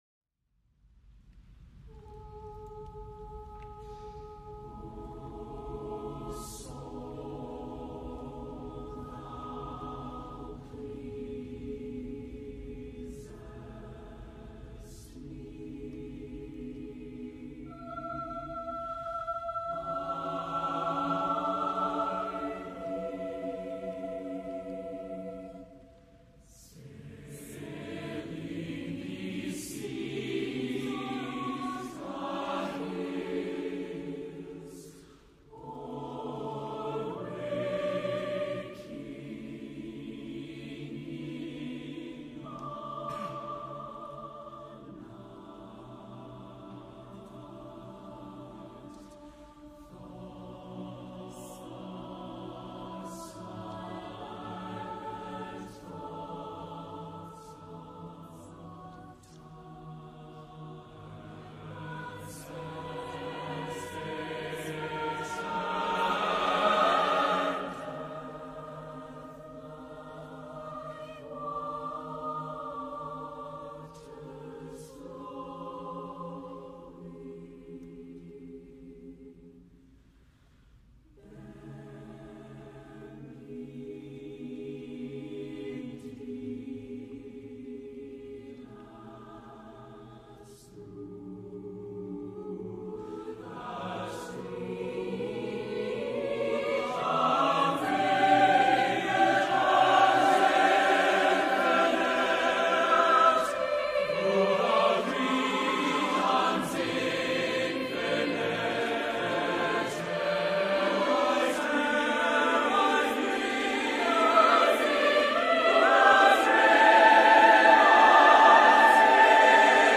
Voicing: SATB divisi